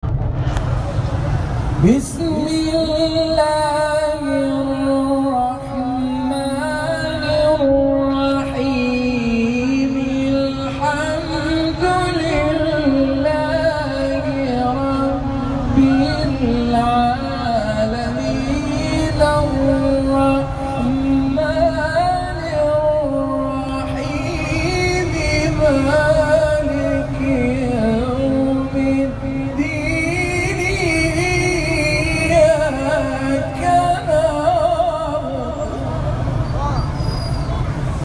شبکه اجتماعی: فرازهای صوتی از تلاوت قاریان ممتاز و بین المللی کشور که به تازگی در شبکه‌های اجتماعی منتشر شده است، می‌شنوید.